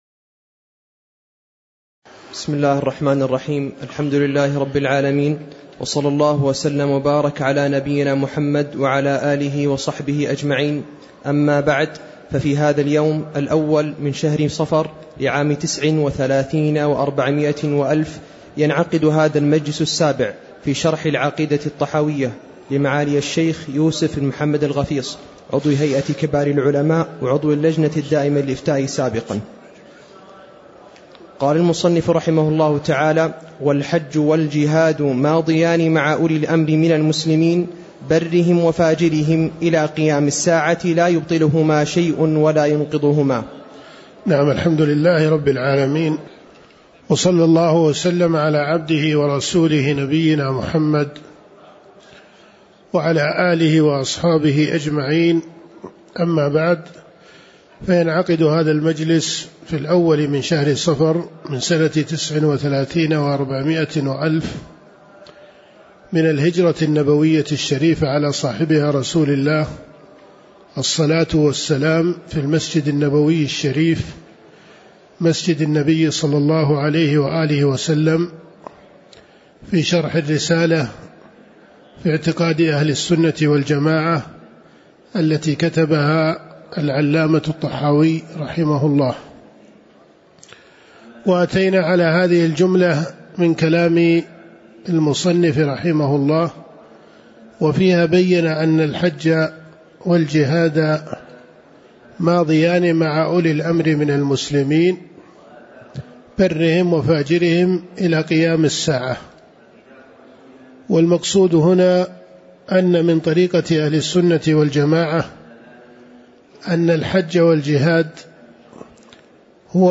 أما بعد: ففي هذا اليوم، الأول من شهر صفرٍ لعام (1439)، ينعقد هذا المجلس السابع في شرح "العقيدة الطحاوية"، لمعالي الشيخ يوسف بن محمد الغَفِيص، عضو "هيئة كبار العلماء"، وعضو "اللجنة الدائمة للإفتاء" سابقًا. الحج والجهاد مع الأئمة برهم وفاجرهم القارئ: قال المصنف رحمه الله تعالى: والحج والجهاد ماضيان مع أولي الأمر من المسلمين برهم وفاجرهم إلى قيام الساعة، لا يبطلهما شيء ولا ينقضهما.